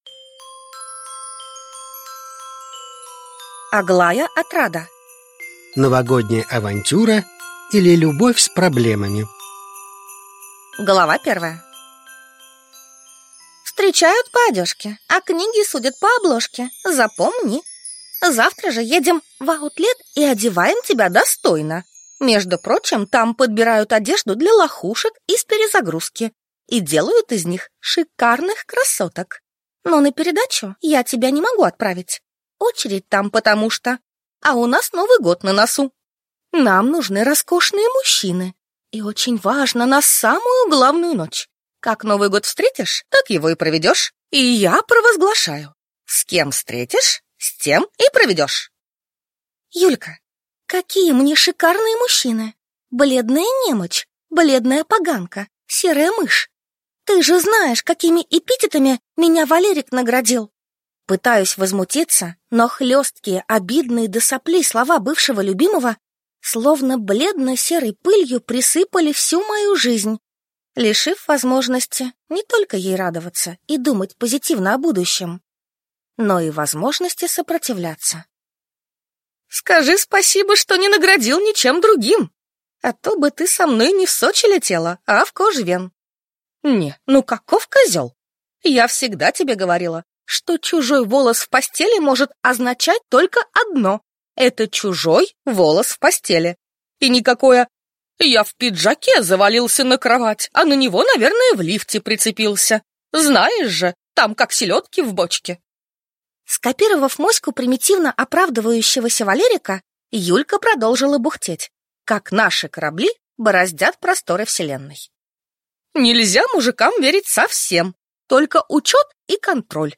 Аудиокнига Новогодняя авантюра, или Любовь с проблемами | Библиотека аудиокниг
Прослушать и бесплатно скачать фрагмент аудиокниги